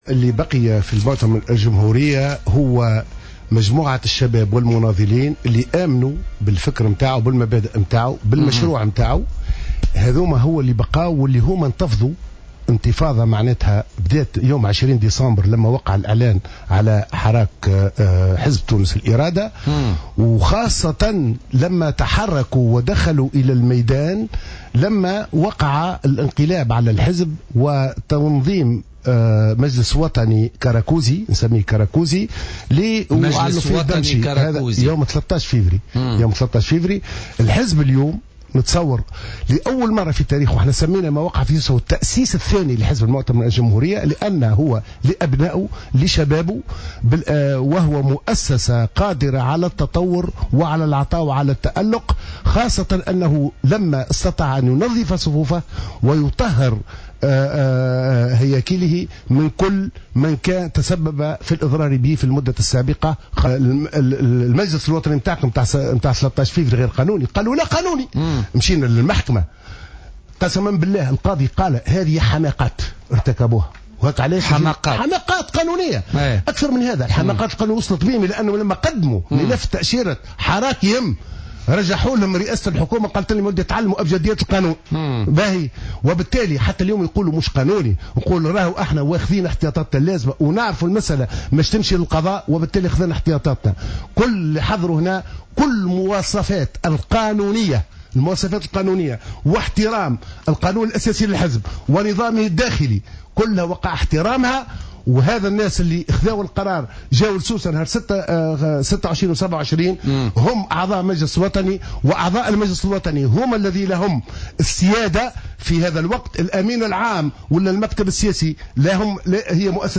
وقال معطر ضيف برنامج "بوليتيكا" اليوم الثلاثاء إن الذين غادروا حزب المؤتمر والتحقوا ب"حراك تونس الإرادة" ارتكبوا حماقات قانونية، مشيرا إلى أن أعضاء المجلس الوطني الاستثنائي الذي انعقد بسوسة يومي 26 و27 مارس الجاري احترم القانون الأساسي والنظام الداخلي للحزب.